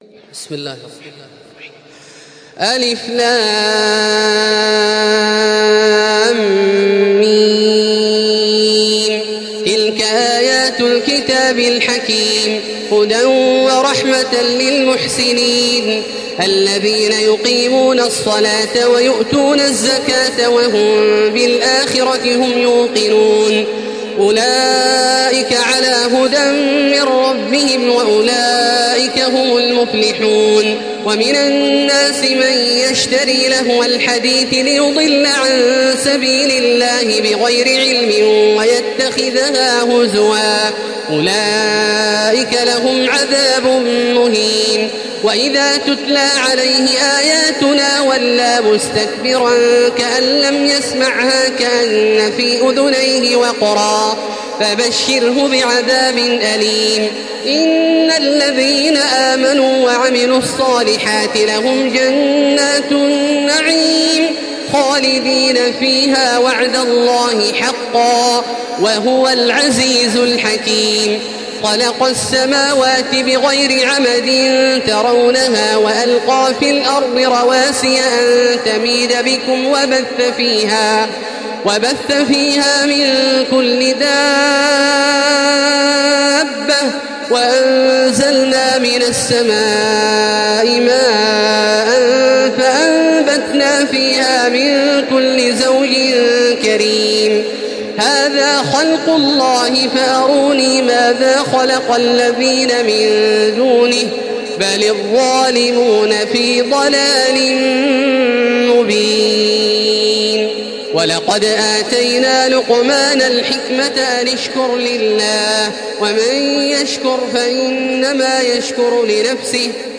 Surah Luqman MP3 in the Voice of Makkah Taraweeh 1435 in Hafs Narration
Murattal